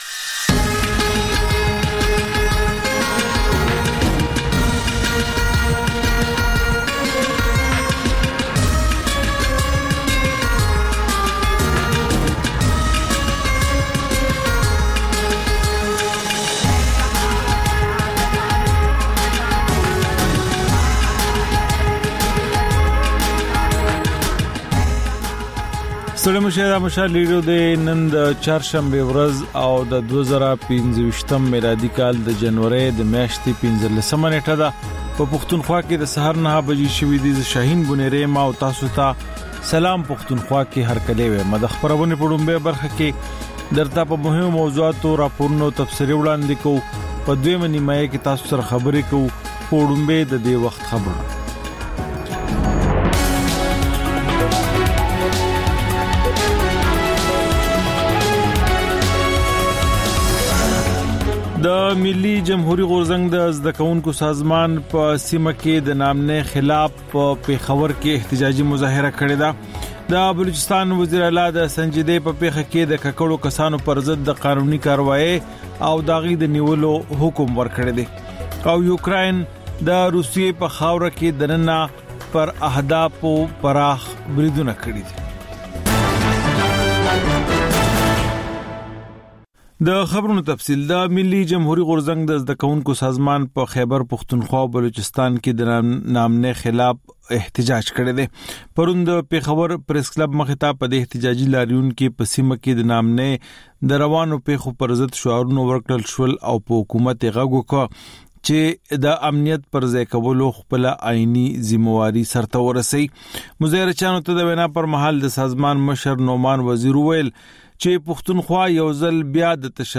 دا د مشال راډیو لومړۍ خپرونه ده چې پکې تر خبرونو وروسته رپورټونه، له خبریالانو خبرونه او رپورټونه او سندرې در خپروو.